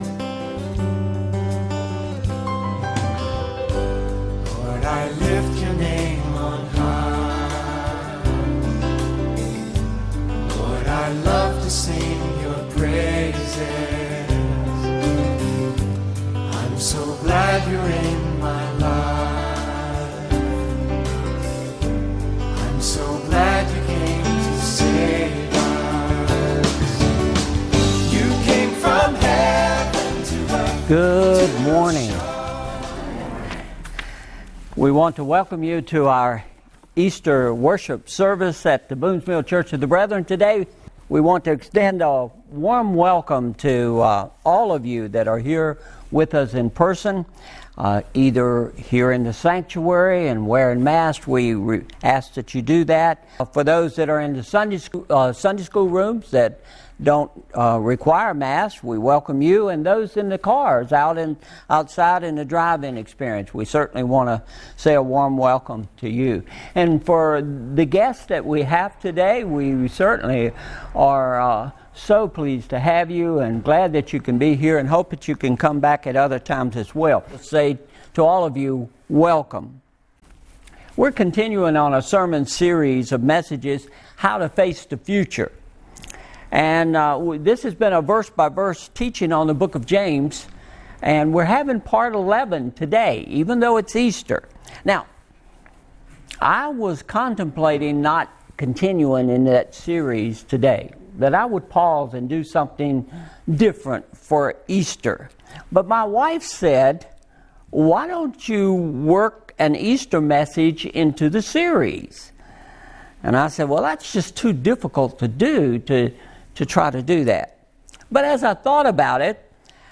Apr 04, 2021 Easter-“Back to the Future” MP3 Notes Discussion Sermons in this Series Sermon Series How to Face Our Future!